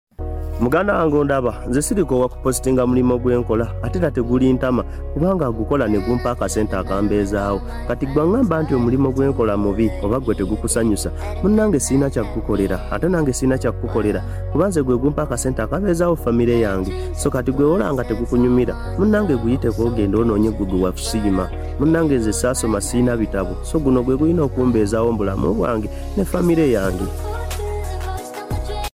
Free wait machines sound effects free download